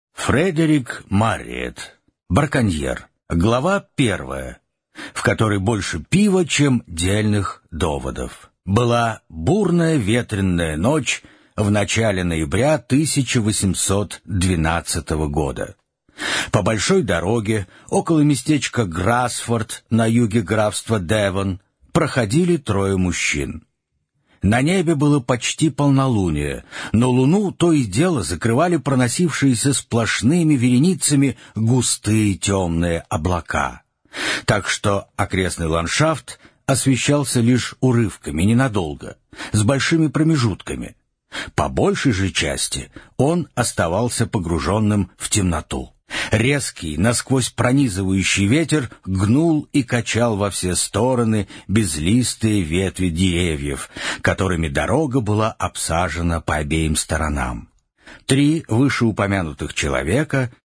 Аудиокнига Браконьер | Библиотека аудиокниг